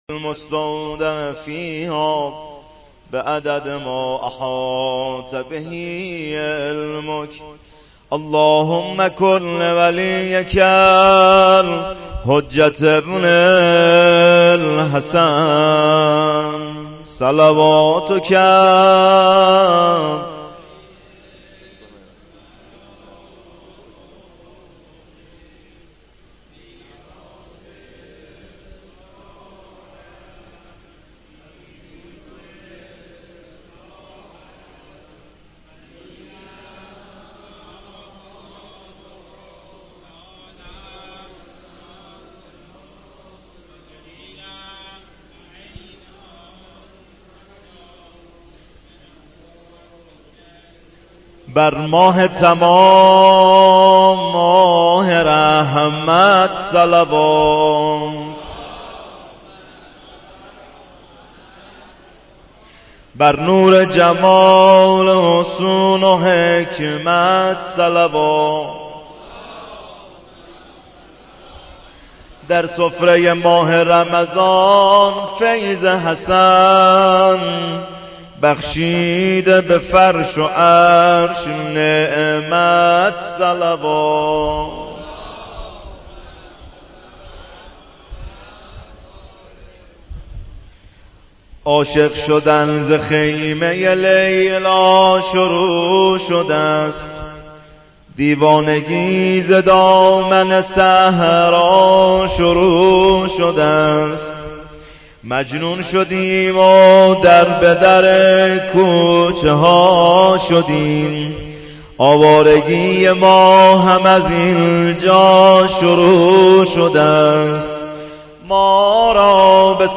مولودی روز پانزدهم ماه مبارک رمضان 1435